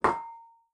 clamour3.wav